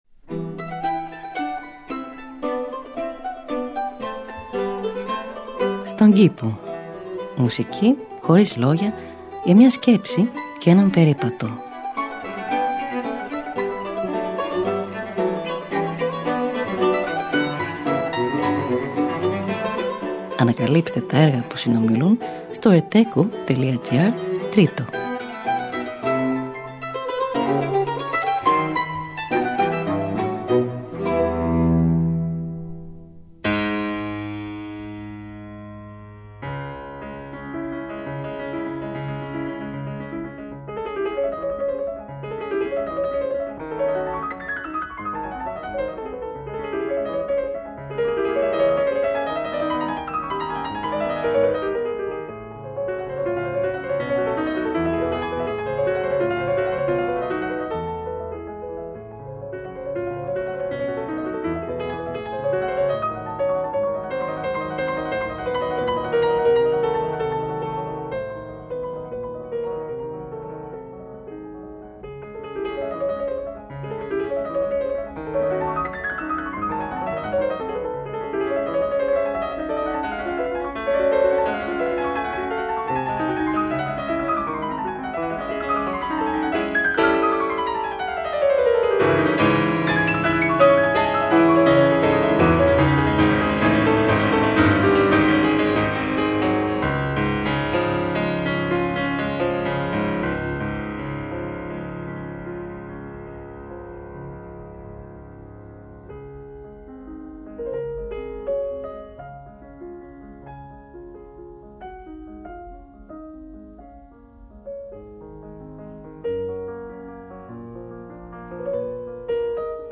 Μουσική Χωρίς Λόγια για μια Σκέψη και έναν Περίπατο.